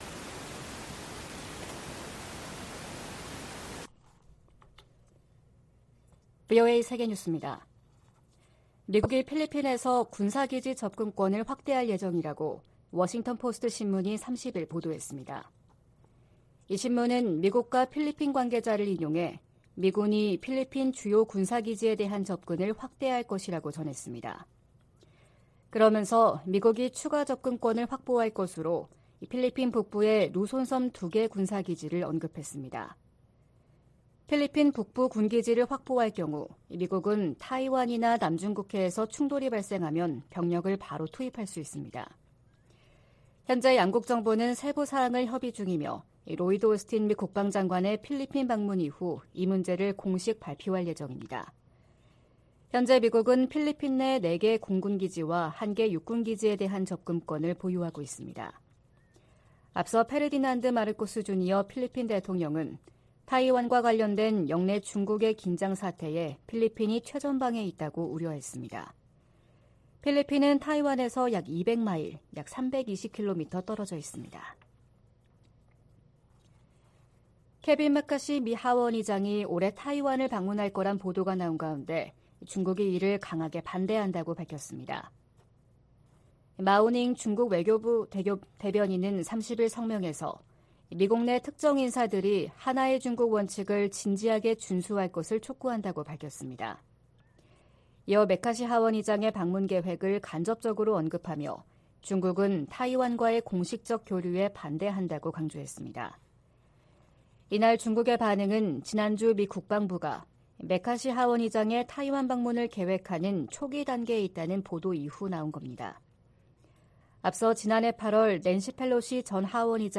VOA 한국어 '출발 뉴스 쇼', 2023년 2월 1일 방송입니다. 미-한 두 나라 국방 장관들이 서울에서 회담을 갖고, 한국에 대한 미국의 확고한 방위공약을 보장하기 위해 미국의 확장억제 실행력 강화 조치들을 공동으로 재확인해 나가기로 했습니다. 북한이 함경남도 마군포 엔진시험장에서 고체연료 엔진 시험을 한 정황이 포착됐습니다.